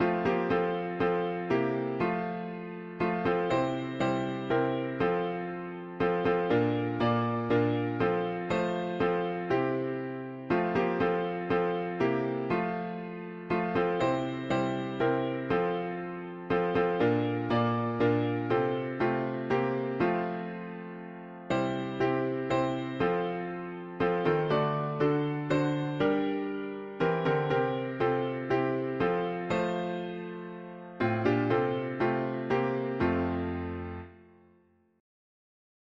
Key: F major